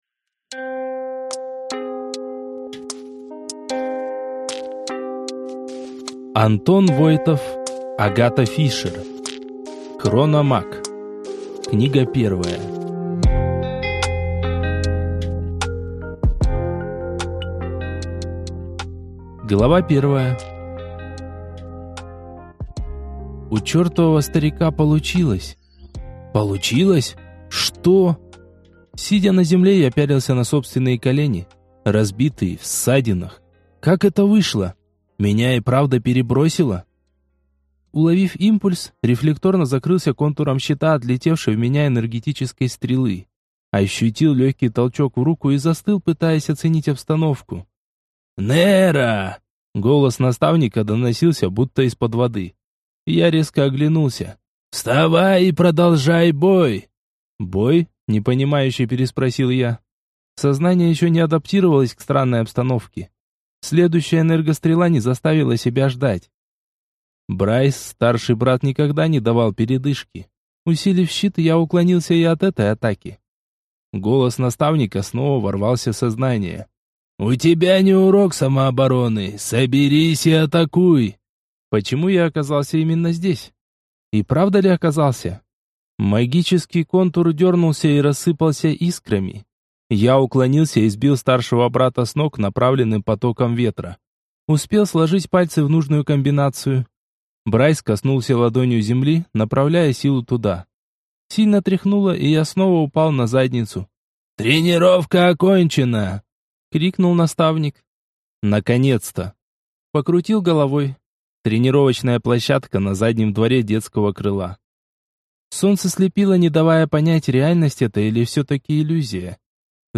Аудиокнига Хрономаг. Книга 1 | Библиотека аудиокниг